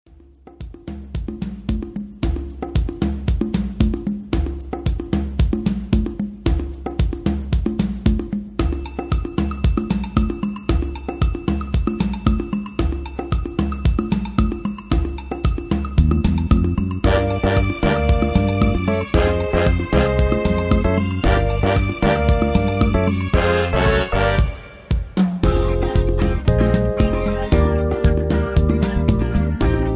backing tracks
karaoke
rock, r and b